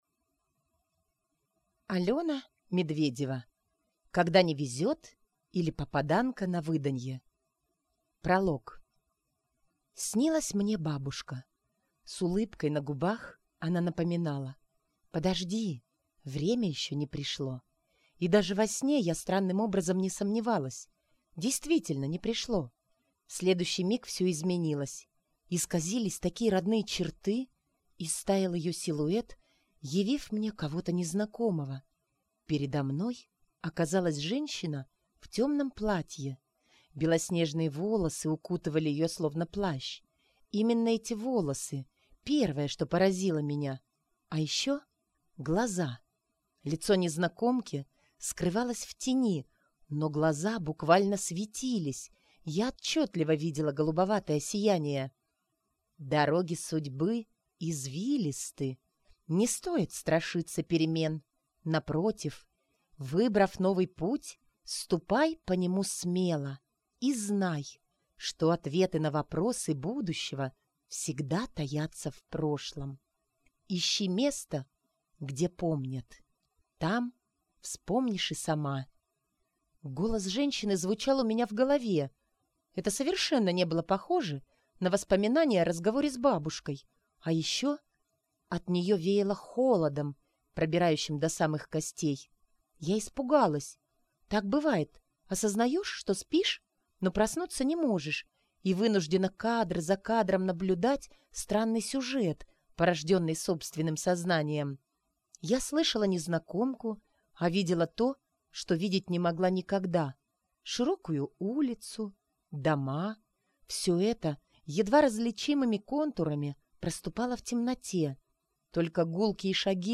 Аудиокнига Когда не везет, или Попаданка на выданье | Библиотека аудиокниг